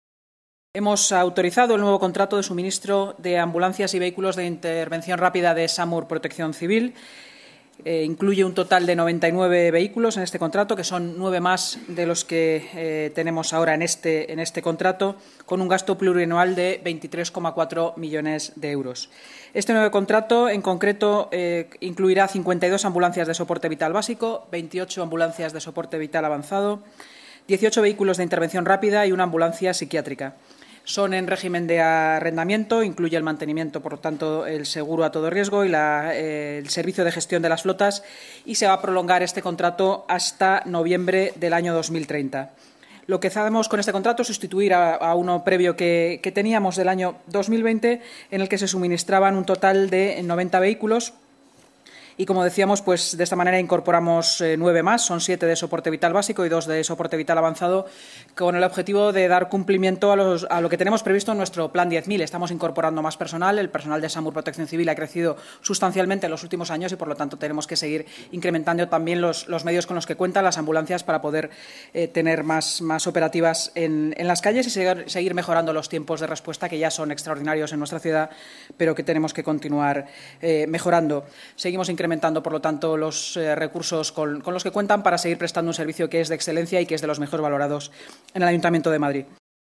Nueva ventana:Declaraciones de la vicealcaldesa de Madrid y portavoz municipal, Inma Sanz